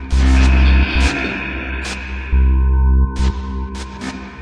碟片FX
描述：我正在收拾餐具并听到一种奇怪的声音......经过调查，我发现我在盘子上堆了一个碟子，发生了一次摆动。用edirol r09hr拍摄
标签： 有趣 效果 现场fecording 声音
声道立体声